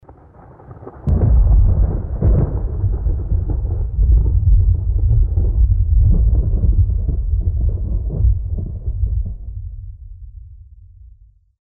thunder2.mp3